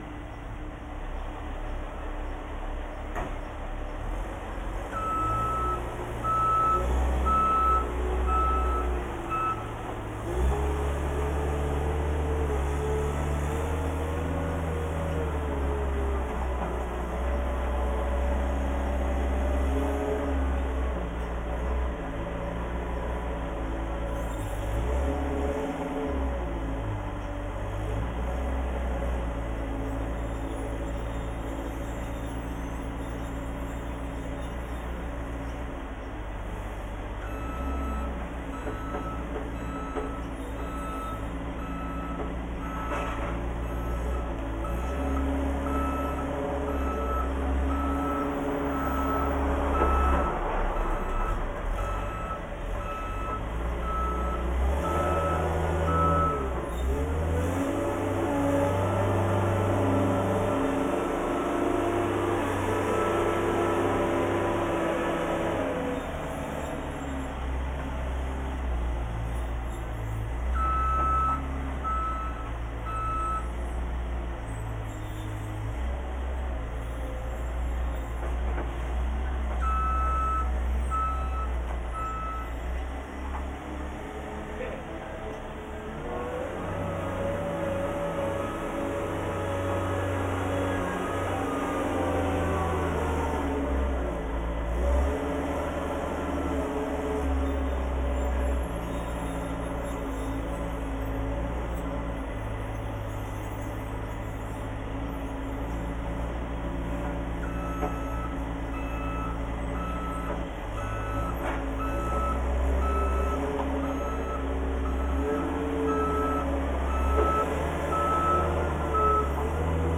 background_construction_forklift_truck_lp.wav